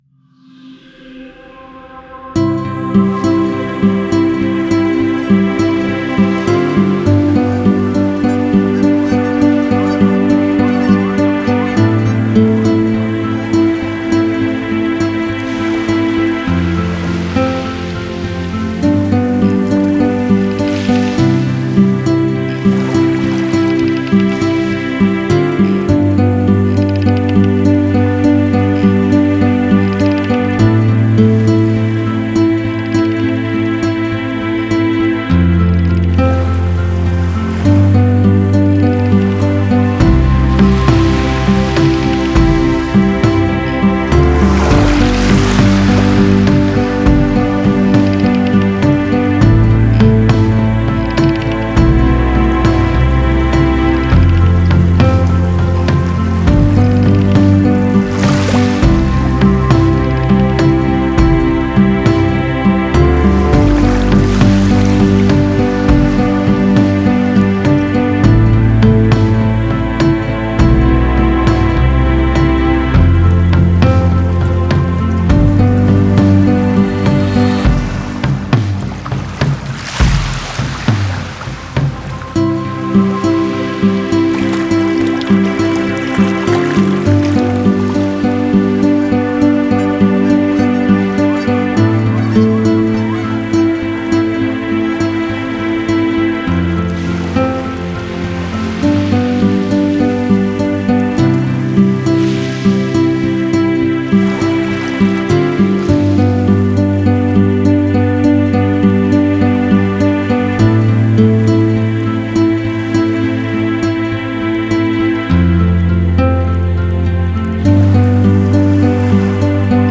AMBIENT SOUND